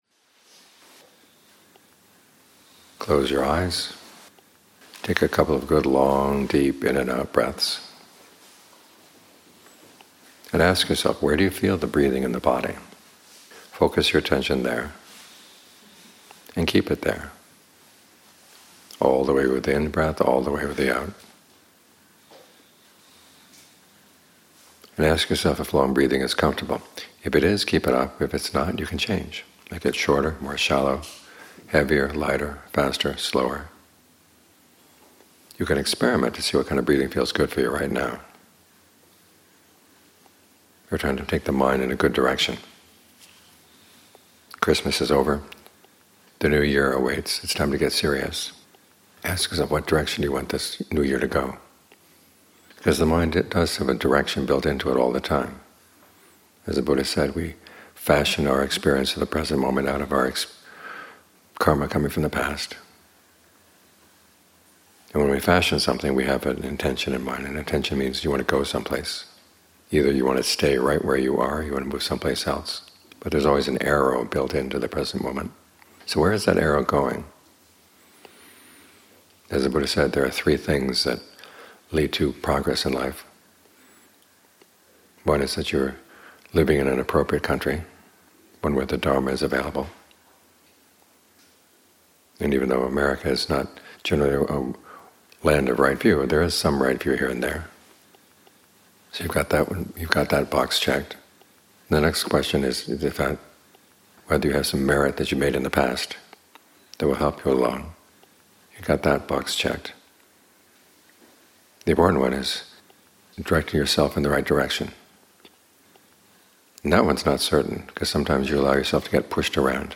Morning Talks